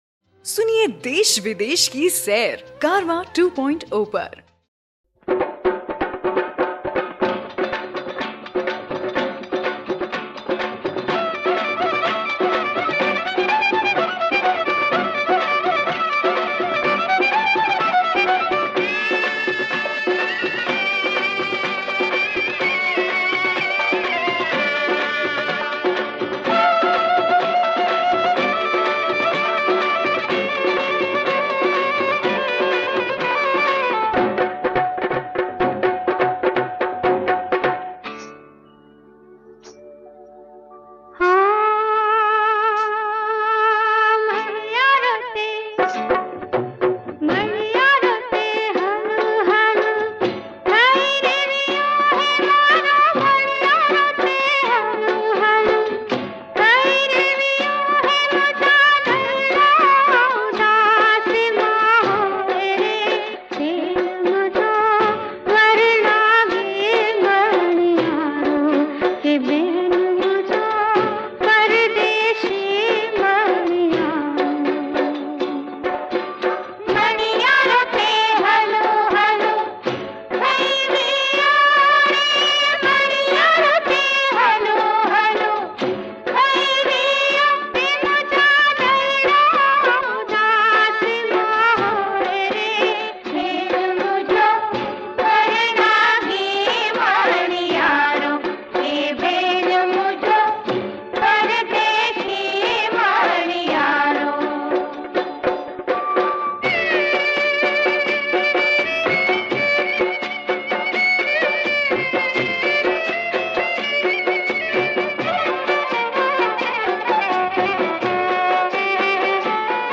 ગીત સંગીત ગરબા - Garba